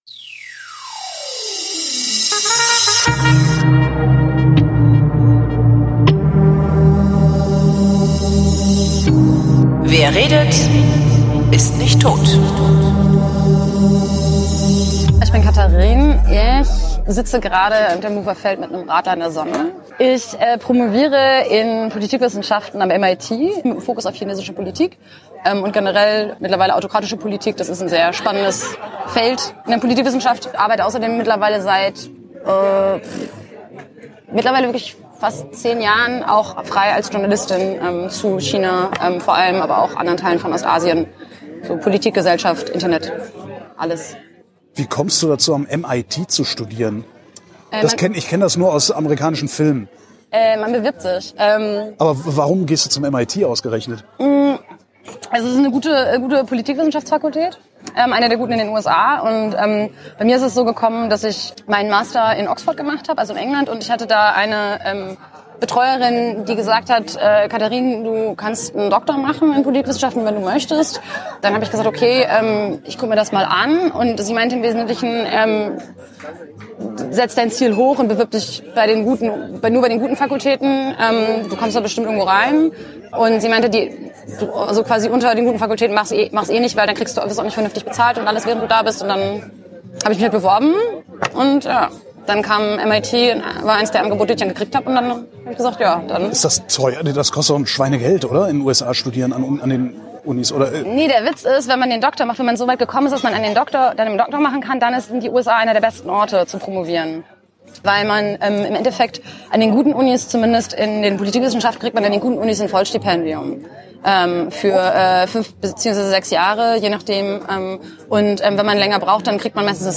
im Biergarten getroffen.